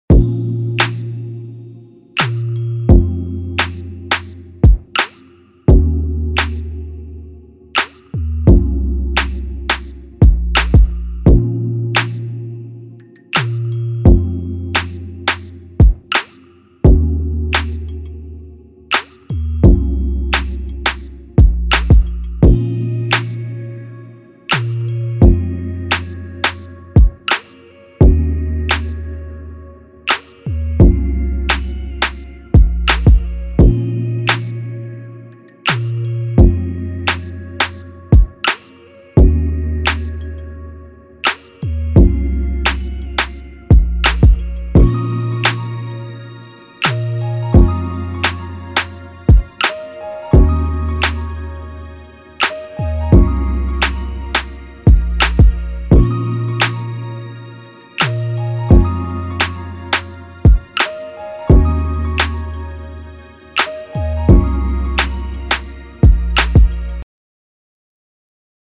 R&B
B# Minor